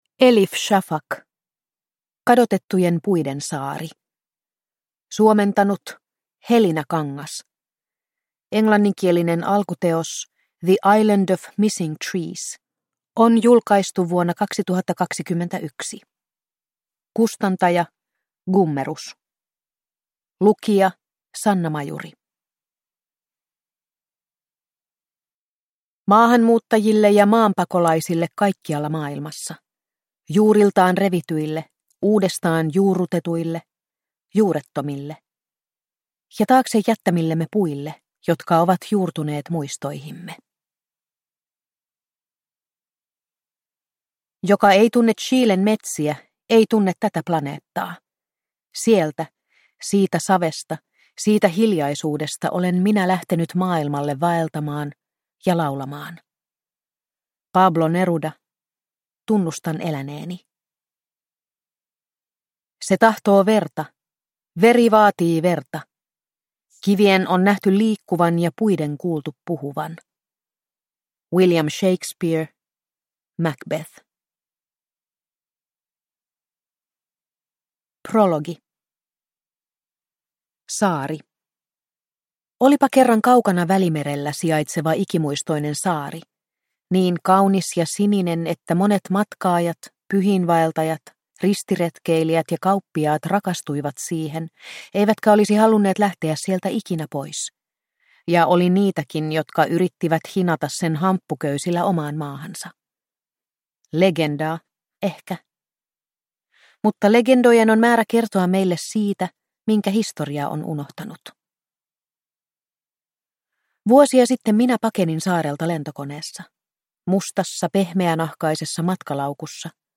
Kadotettujen puiden saari – Ljudbok – Laddas ner